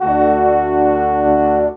Rock-Pop 01 Brass 02.wav